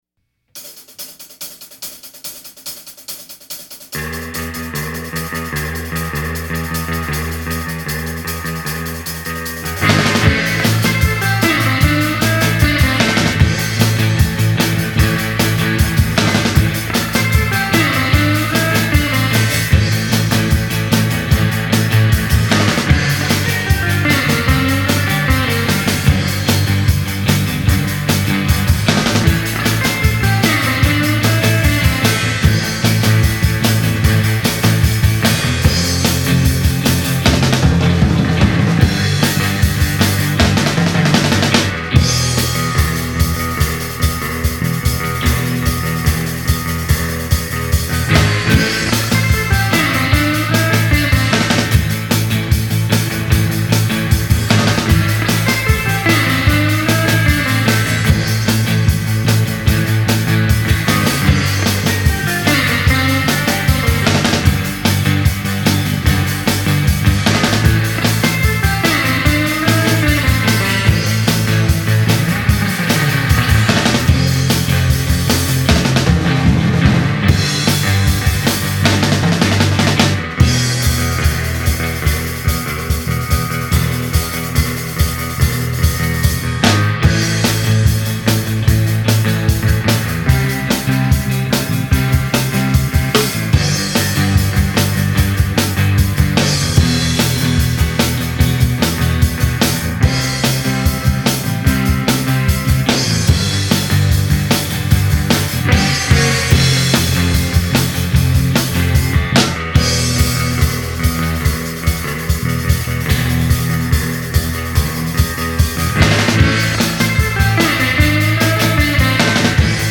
Rock
Instrumental Surf Rock